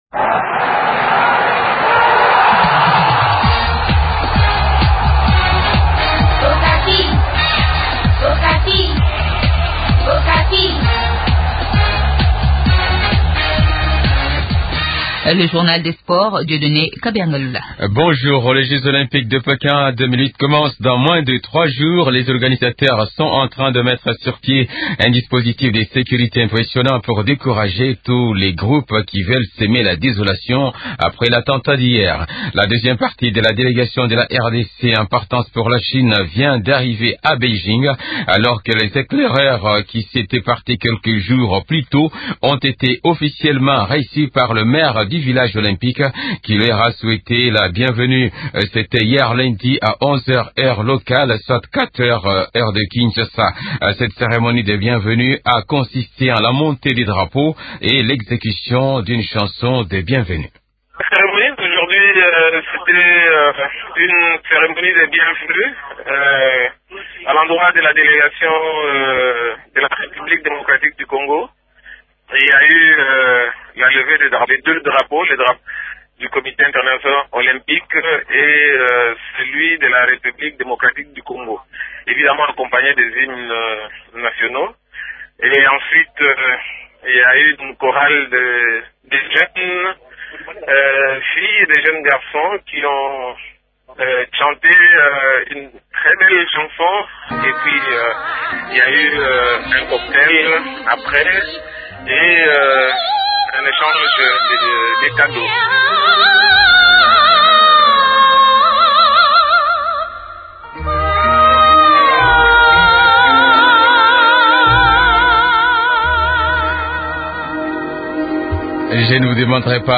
Journal des Sports